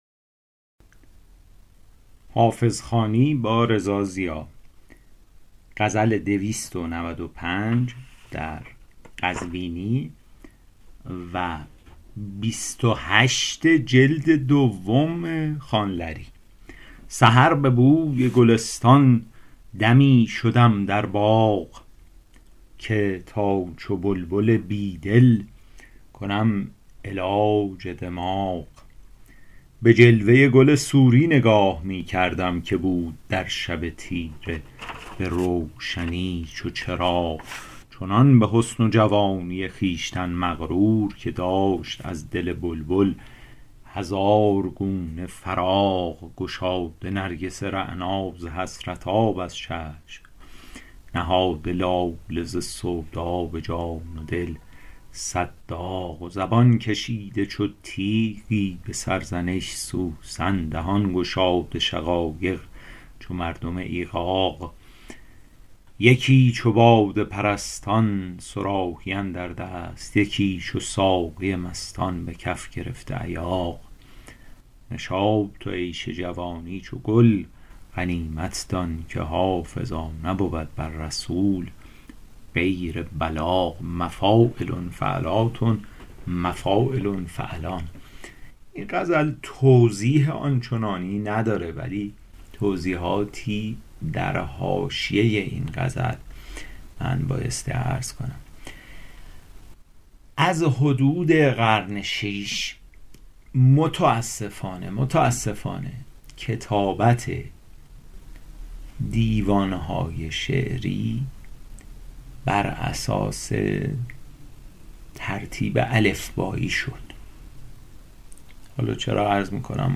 حافظ غزلیات شرح صوتی